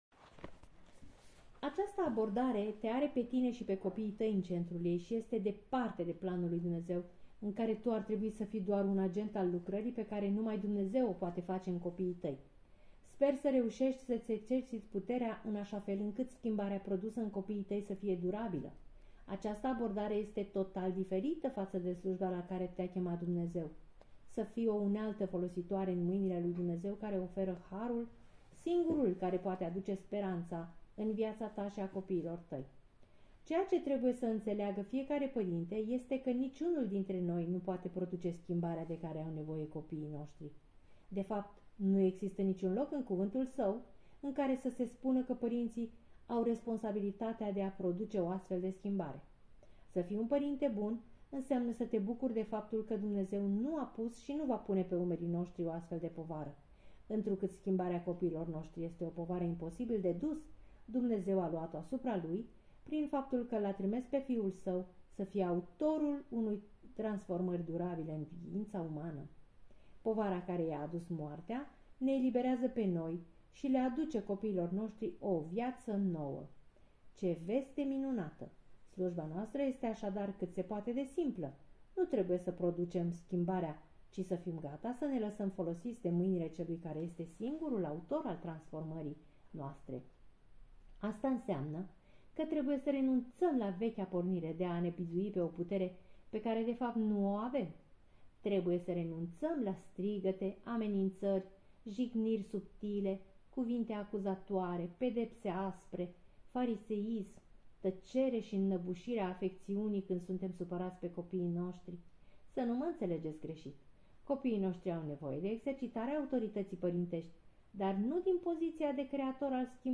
Capitolul este citit